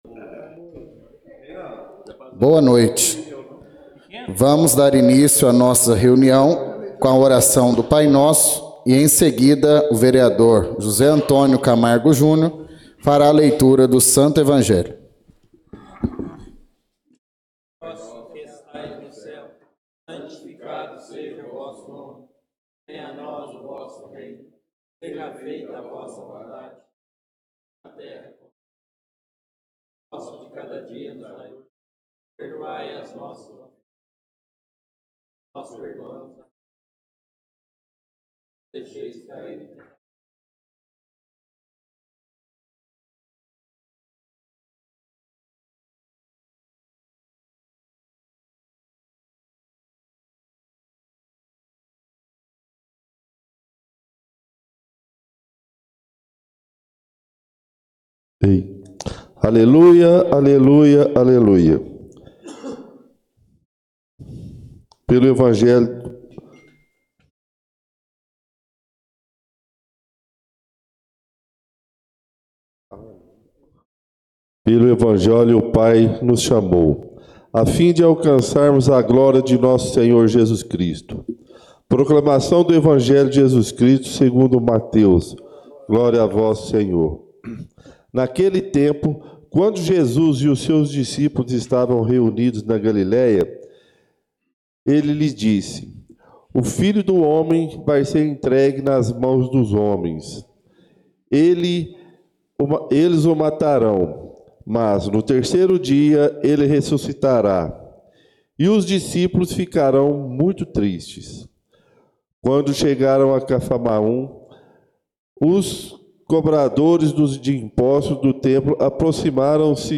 Por haver número legal, em nome do povo de Piumhi e sob a proteção de Deus, declaro aberta a 26ª Sessão Ordinária neste dia 12 de agosto de 2024.